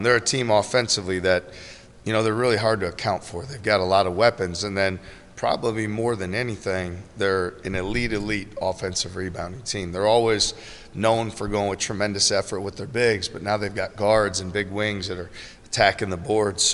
That's ISU coach T.J. Otzelberger, who says rebounding will be a crucial part of the game.